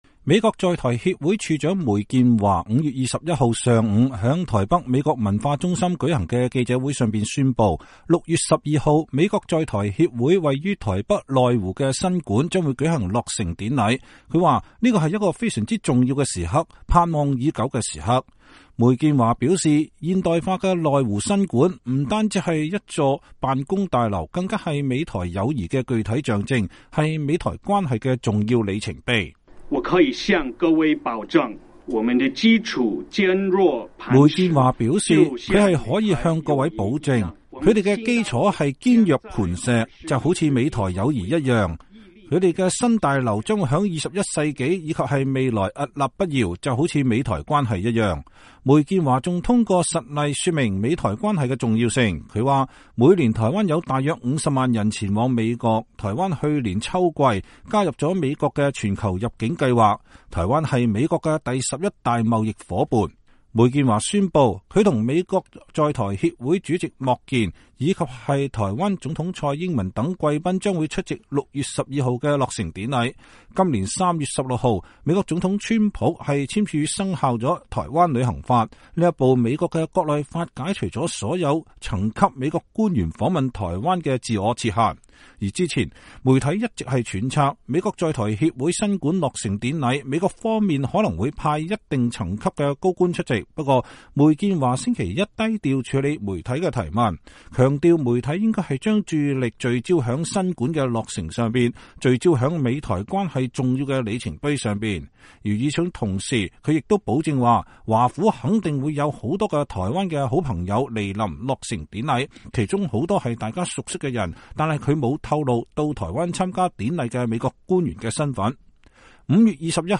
梅健華5月21日上午在台北美國文化中心舉行的記者會上宣布，6月12日美國在台協會位於台北內湖的新館將舉行落成典禮。